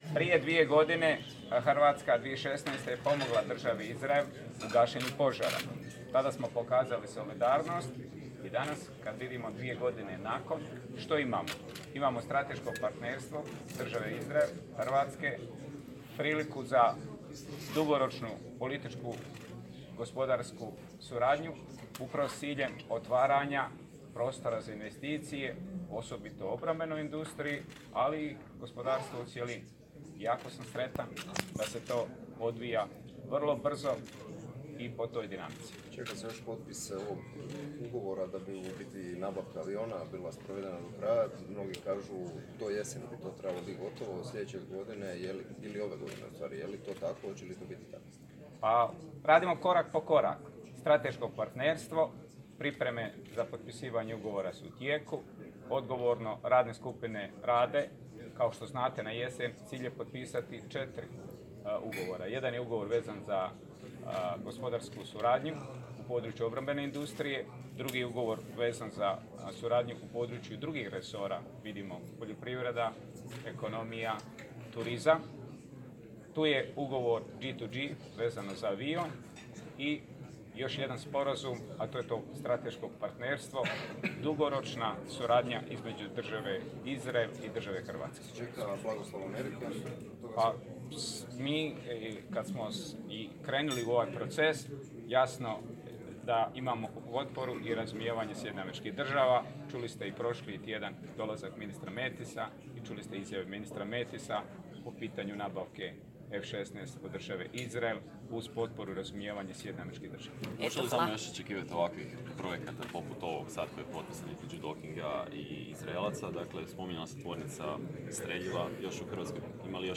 Audio izjava potpredsjednika Vlade RH i ministar obrane Damir Krstičević tijekom konferencije